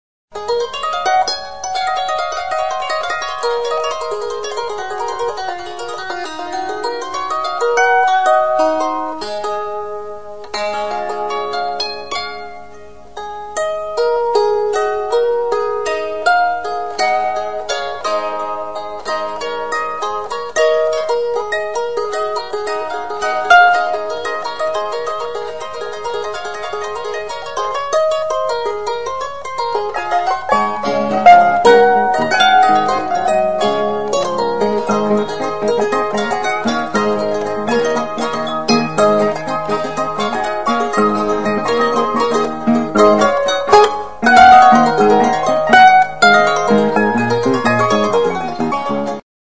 Stringed -> Plucked